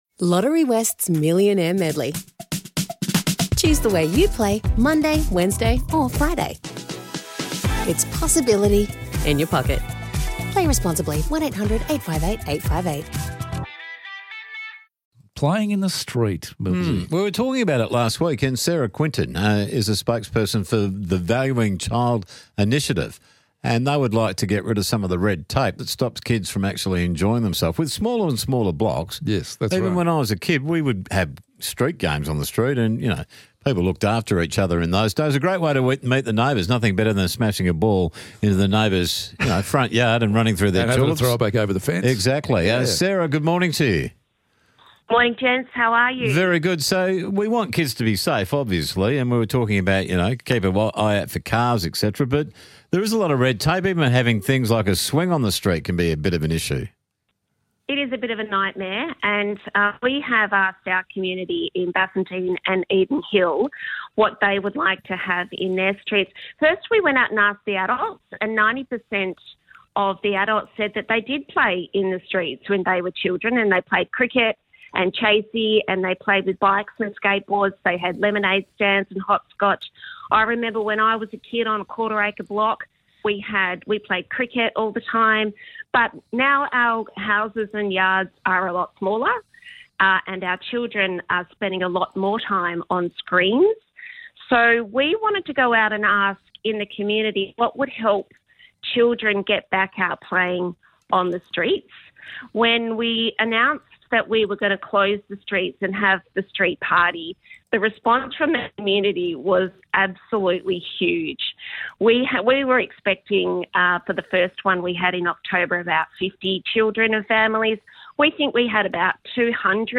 The Valuing Children Initiative spoke with 6PR about how we can reclaim our streets for play, strengthen communities, and create safer spaces for children.